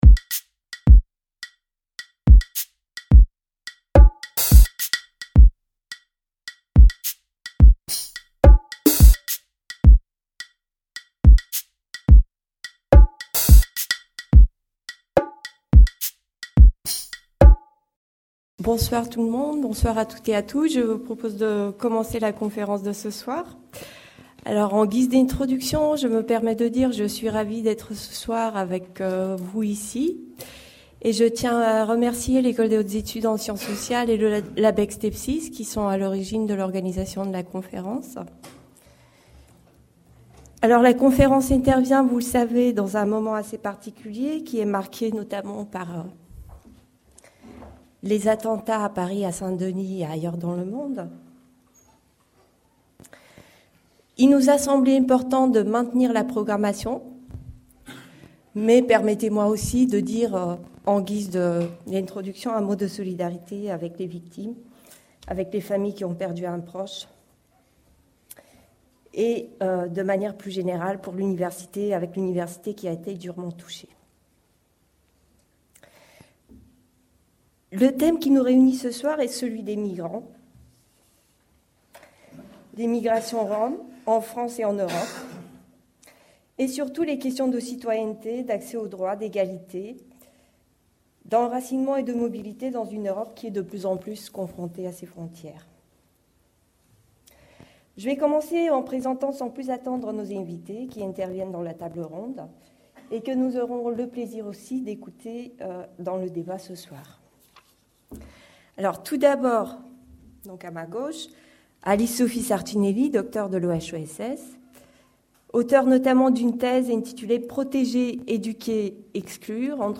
Cycle de conférences organisé par le LabEx Tepsis Les Agendas du Politique